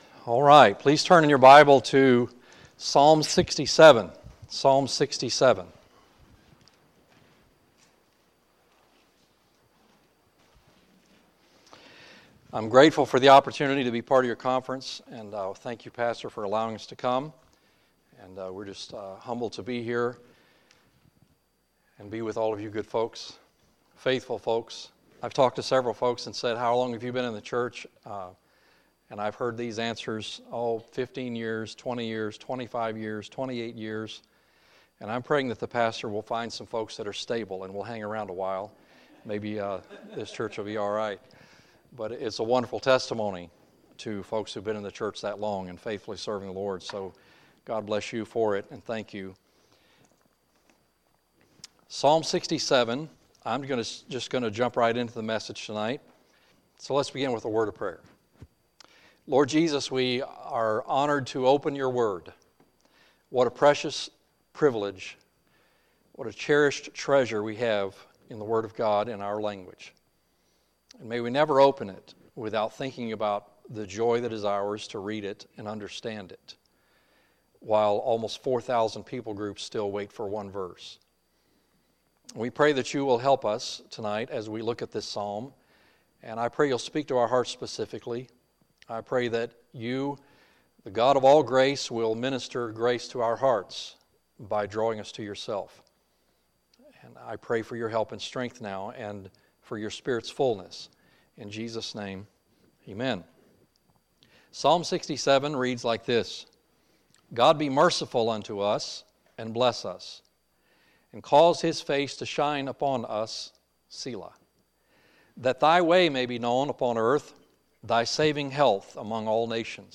Wednesday, September 26, 2018 – Wednesday Evening Service
Sermons